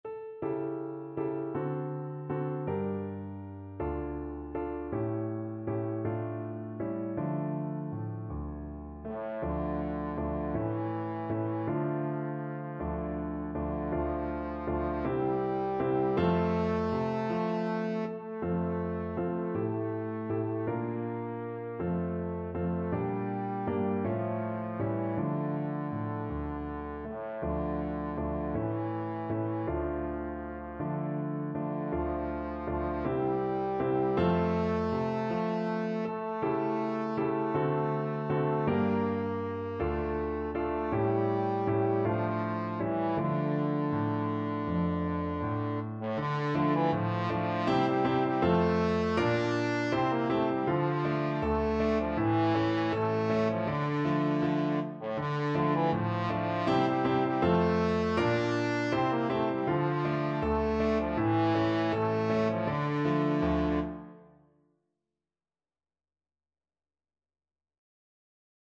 Trombone
D minor (Sounding Pitch) (View more D minor Music for Trombone )
6/8 (View more 6/8 Music)
Andante
Traditional (View more Traditional Trombone Music)